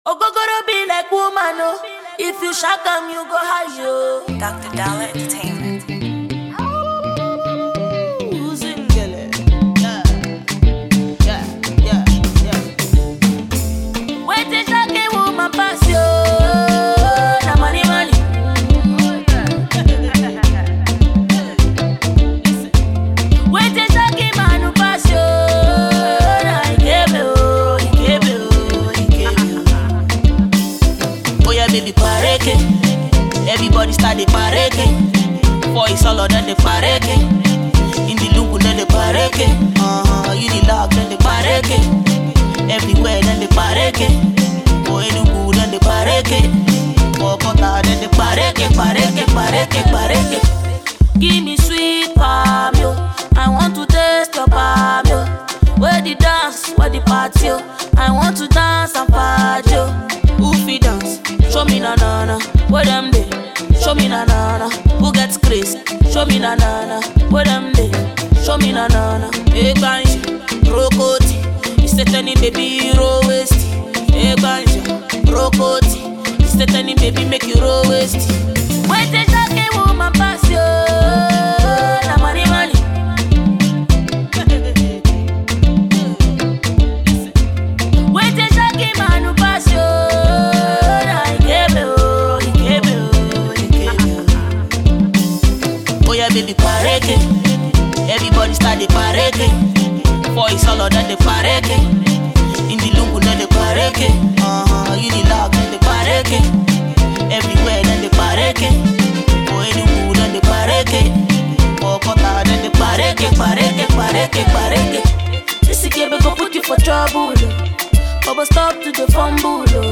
rising female artiste
very street-ready tune